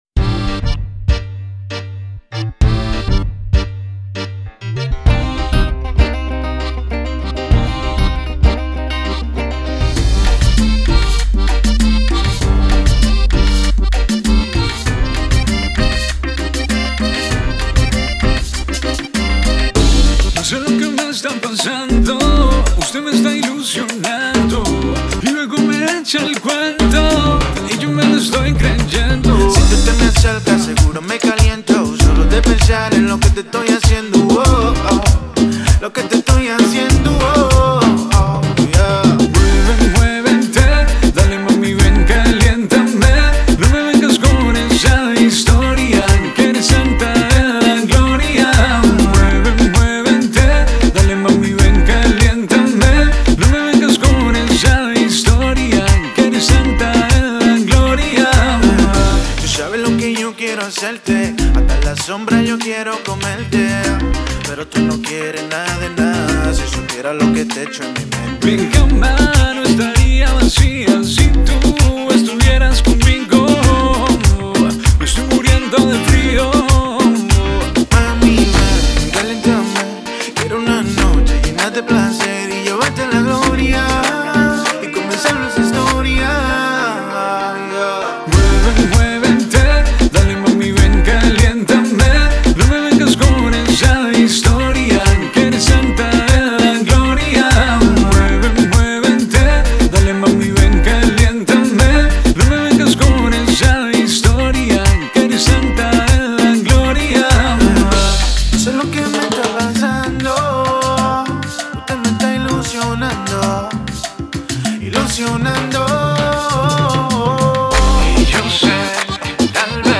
Home > Music > Pop > Bright > Romantic > Medium Fast